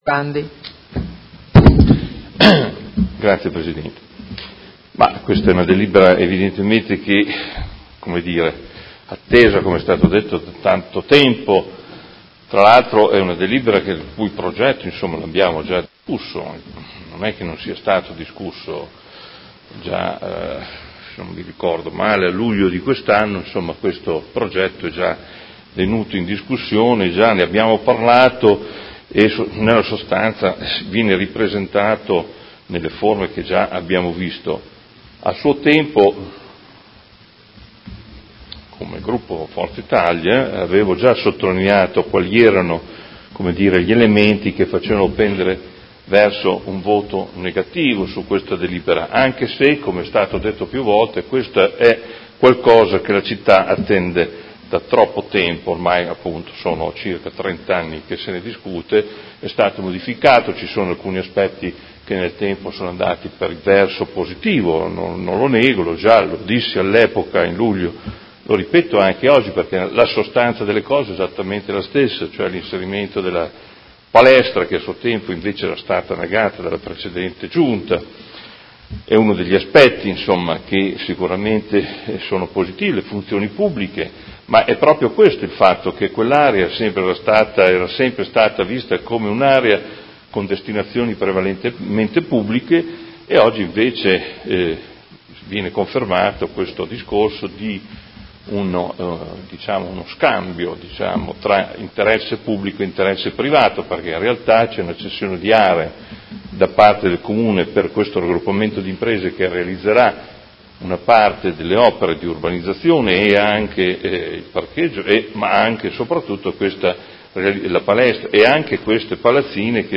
Seduta del 17/12/2018 Dibattito. Delibera: Adozione del Programma di Riqualificazione Urbana (PRU) in variante al Piano Operativo Comunale (POC) e al Regolamento Urbanistico Edilizio (RUE), all'esito della procedura competitiva con negoziazione ai sensi dell'articolo 62 del D.Lgs. 18/04/2016 n. 50, per la progettazione e l'esecuzione dell'intervento di riqualificazione, recupero e rigenerazione urbana del Comparto Ex sede AMCM – Parco della Creatività